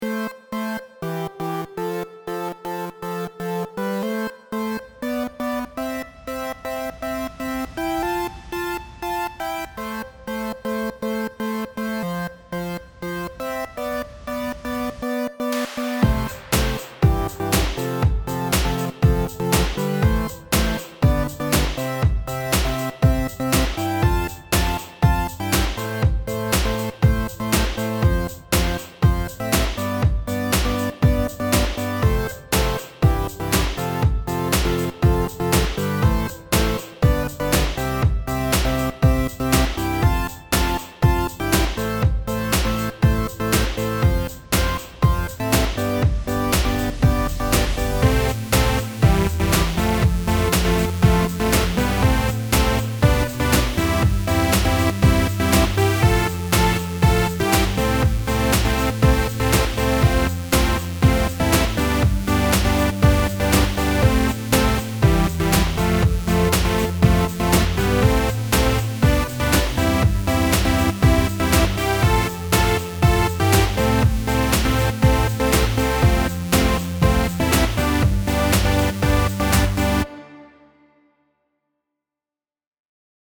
Free Run [8 bit(ish)]